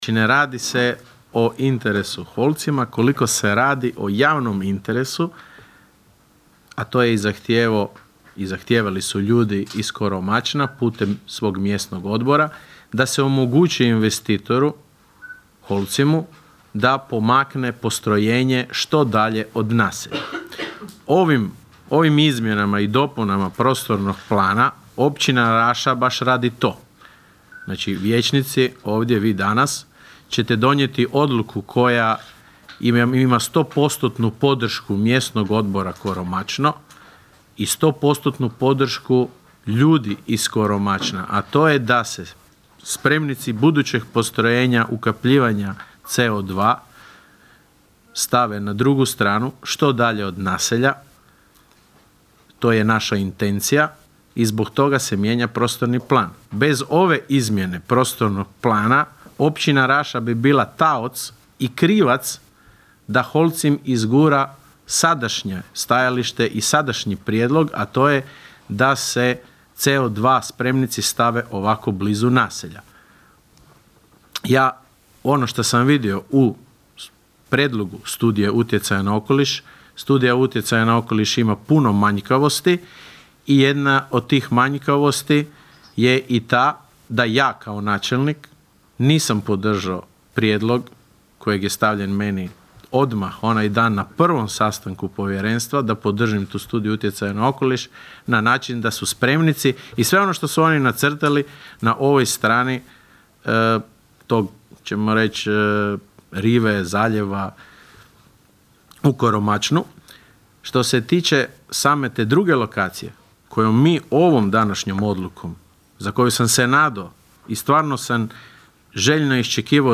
Prvim Izmjenama i dopunama Odluke o izradi 5. Izmjena i dopuna Prostornog plana Općine Raše, usvojenima na sinoćnjoj izvanrednoj sjednici Općinskog vijeća, stvorene su pretpostavke za izmještanje planiranih Holcimovih spremnika ukapljenog plina na drugu stranu zaljeva.
Odgovorio je općinski načelnik Leo Knapić: (